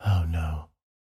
Звук мужского голоса с переживанием, говорящего